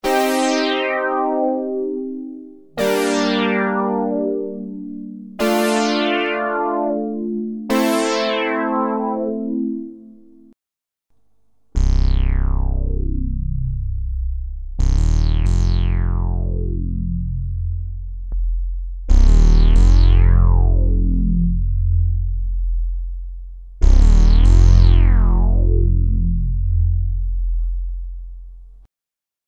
Sweep sounds, poly and then bass unison with different chorus (off, I and II)
Unease_Juno-106_sweep.mp3